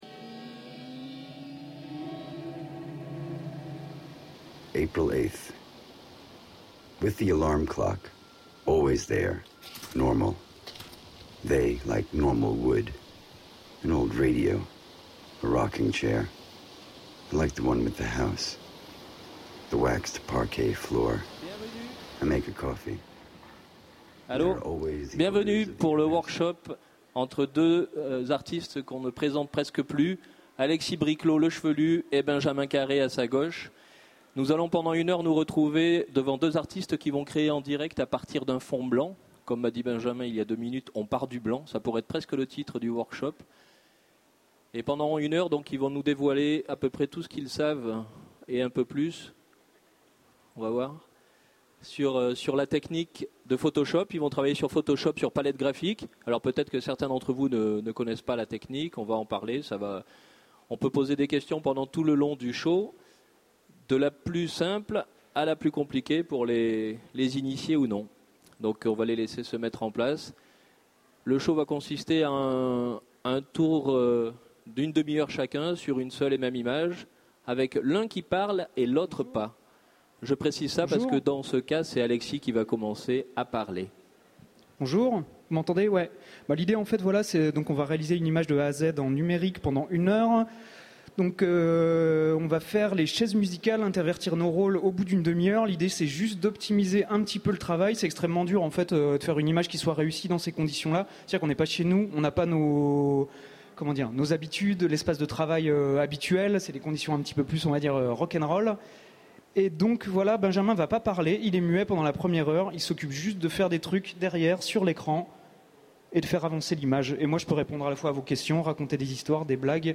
Utopiales 2011 : Conférence Workshop (audio)